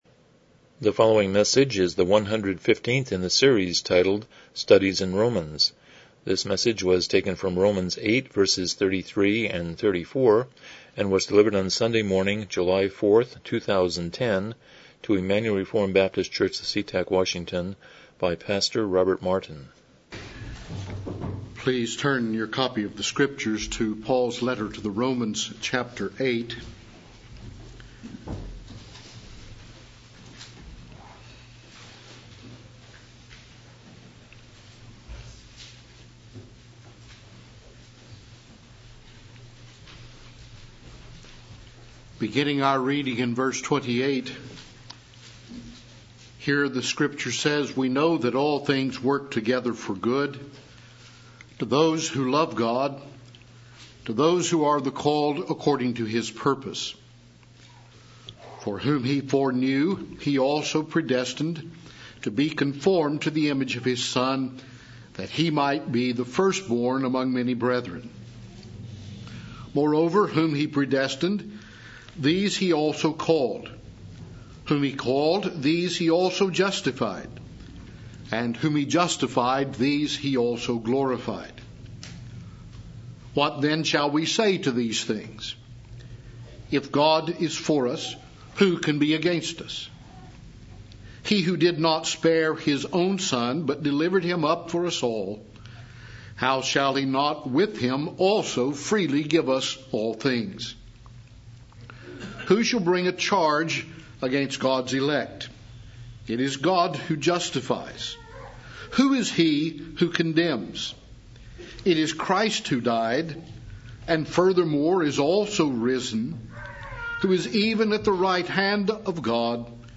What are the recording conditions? Romans 8:33-34 Service Type: Morning Worship « 4 Philippians 1:8 101 Chapter 19.4